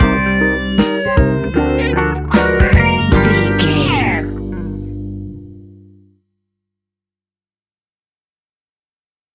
Epic / Action
Fast paced
In-crescendo
Uplifting
Ionian/Major